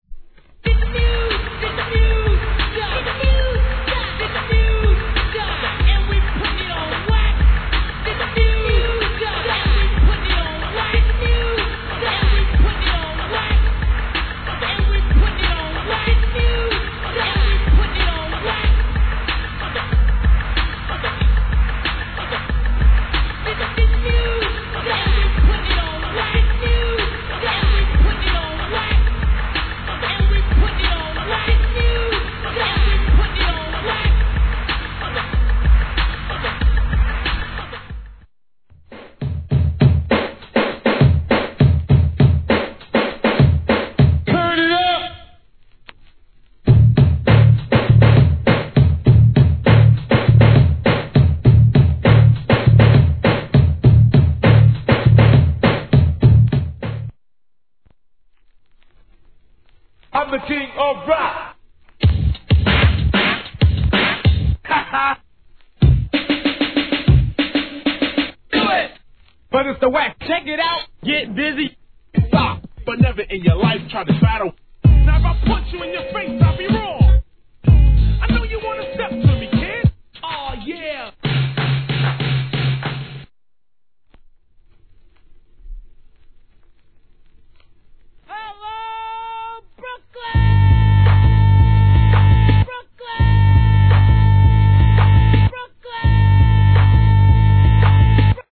HIP HOP/R&B
サンプリング・ネタ、ブレイク・ビーツ、コスリ・ネタ集です。